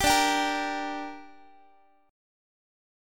Listen to Dsus4#5 strummed